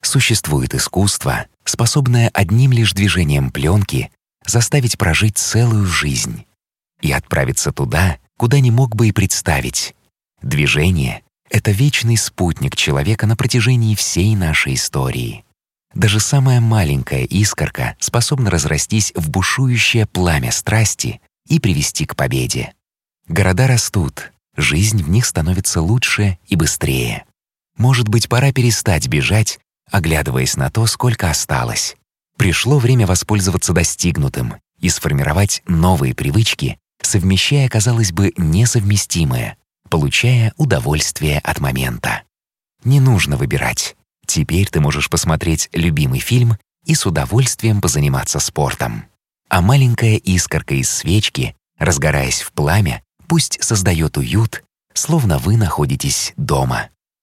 Микрофон: RODE NTK
Качественный тракт, состоящий из микрофона Rode Ntk и звуковой карты TC Electronic Impact Twin вкупе дают отменное звучание голоса.